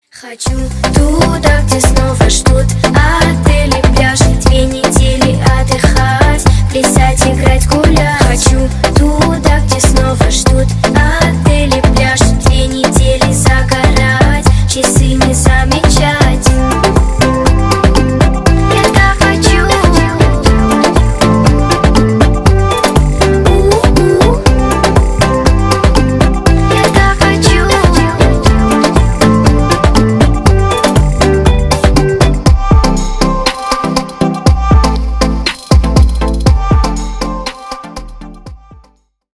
весёлые